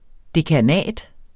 Udtale [ dekaˈnæˀd ]